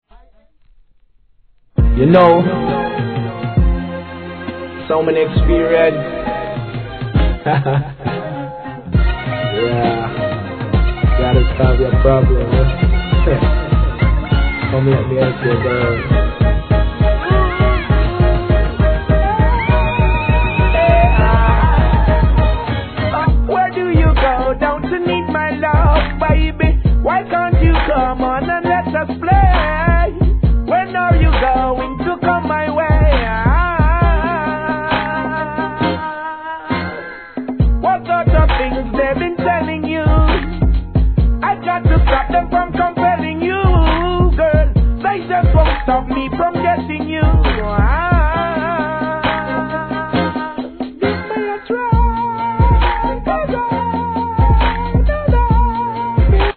HIP HOP/R&B
HIP HOP x REGGAEブレンド・シリーズ第14段!!